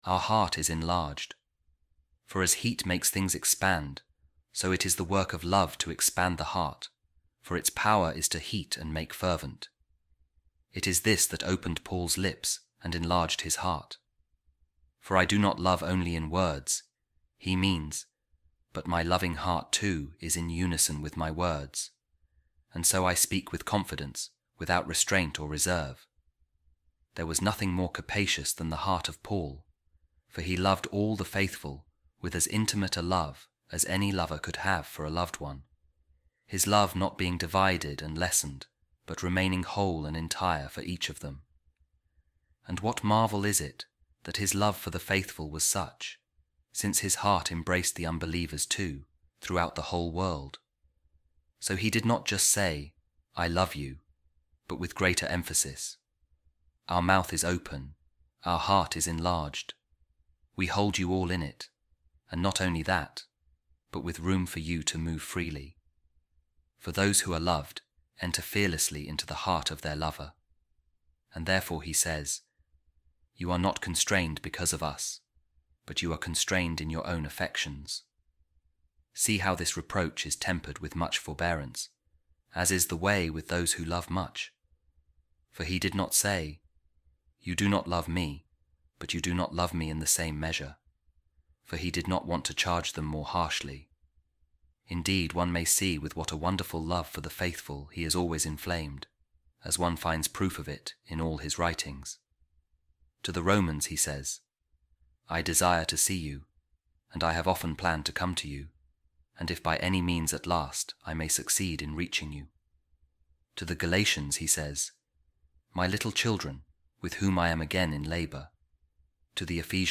A Reading From The Homilies Of Saint John Chrysostom On The Second Letter To The Corinthians